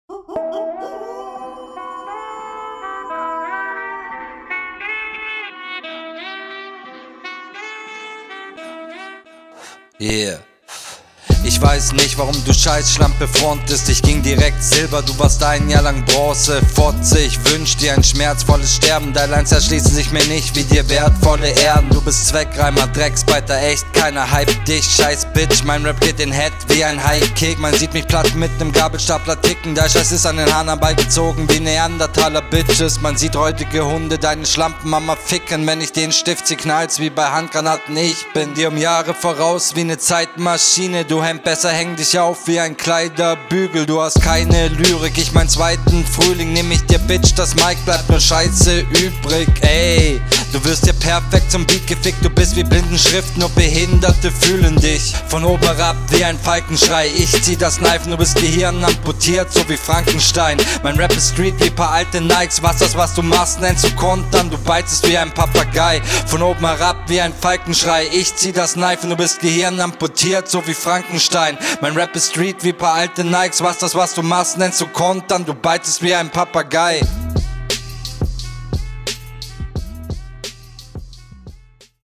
Was ist dieses (unangenehme) Wolf Intro? oof Du nennst ihn Zweckreimer, aber droppst dann Sachen …
Fand der Beat passt besser zu dir, der Flow leider immer noch recht wackelig.